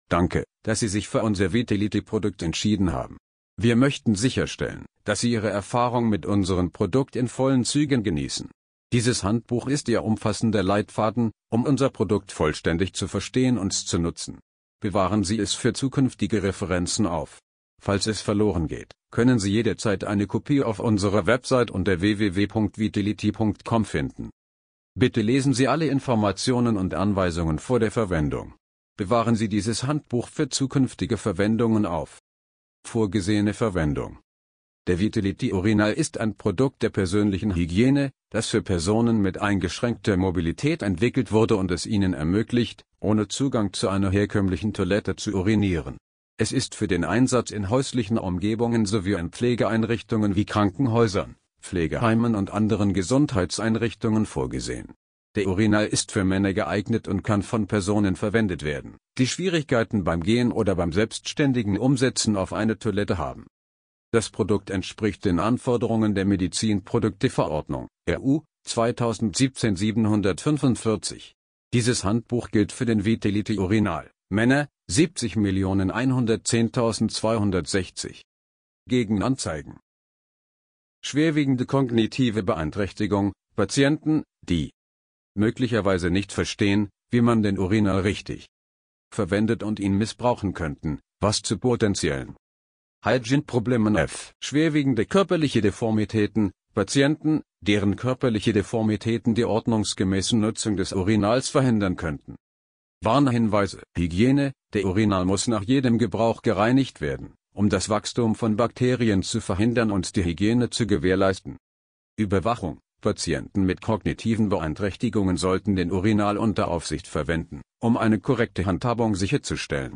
Dänisch Niederländisch Bedienungsanleitung Estnisch Finnisch Französisch Gesprochene deutsche Bedienungsanleitung (MP3) Italienisch Lettisch Litauisch Norwegisch Portugiesisch Russisch Slowenisch Spanisch Schwedisch GPSV Dokument